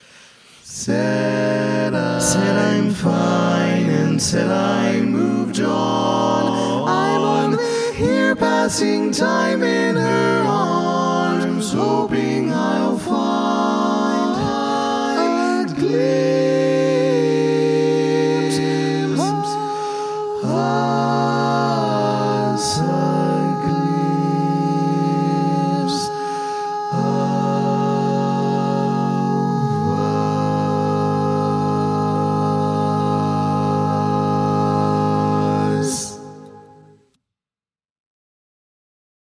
Key written in: A♭ Major
How many parts: 4
Type: Barbershop
All Parts mix:
Learning tracks sung by